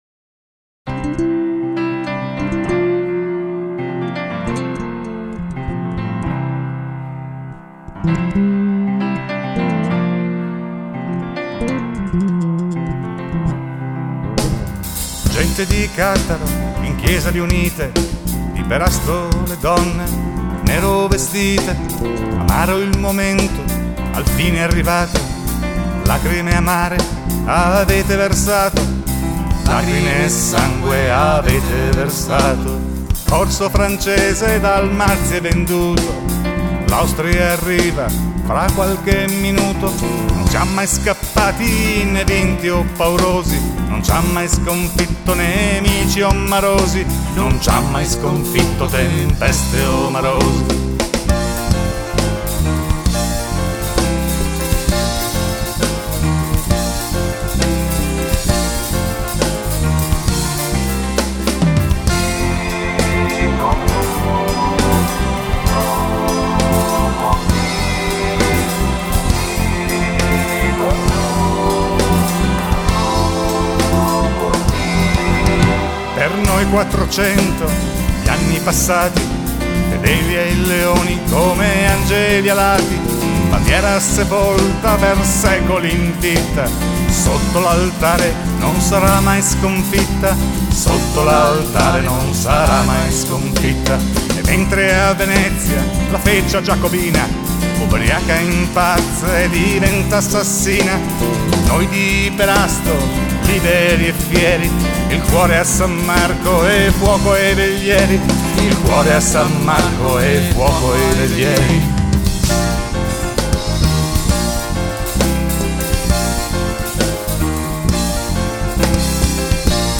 inno.mp3